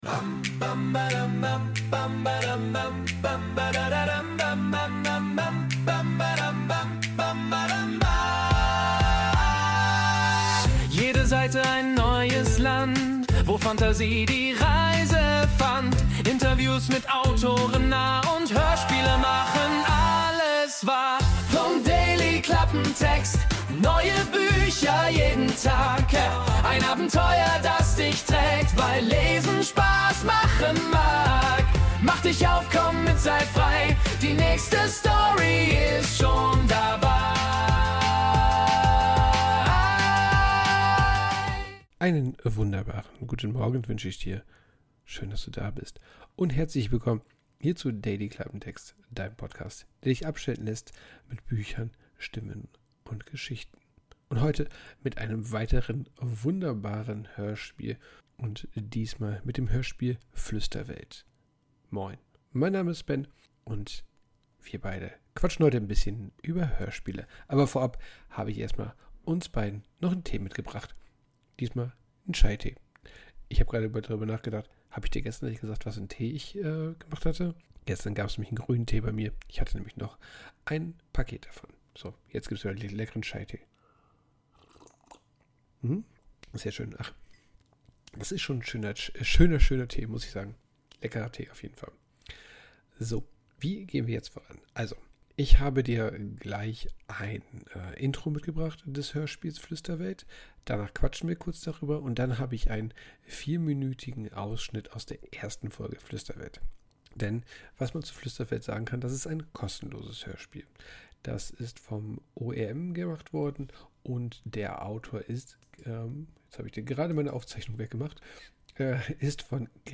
Text: Eigener Text Cover des Hörspiel: Link (Copyright liegt bei den Autoren des Hörspiels) Hörspielauszüge: Trailer und teile der 1 Folge.